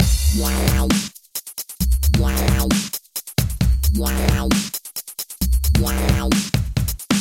Step 1 – Bit Crushing
Applying some bit crushing to our sound is a great way to really rough it up, and fits in nicely with the 8-bit sound that is heard in a lot of electronic music at the moment.
Now increase the sample divider and hear the effect it has on the sound.